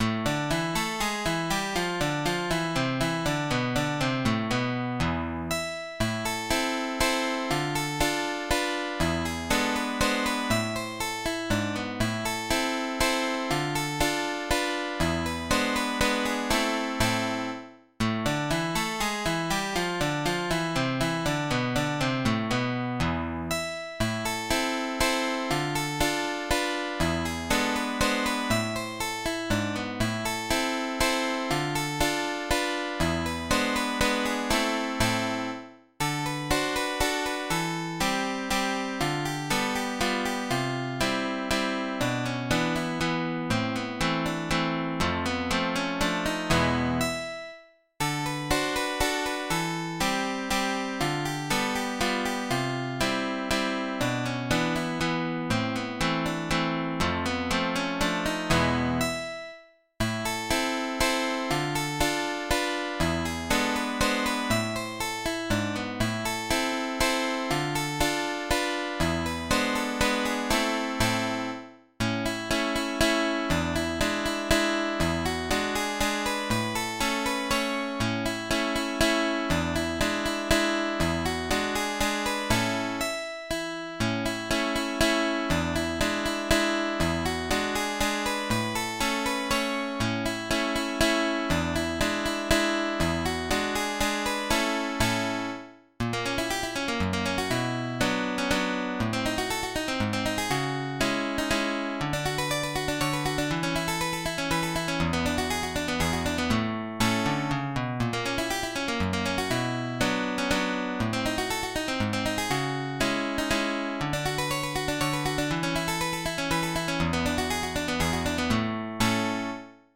Mazurka[tab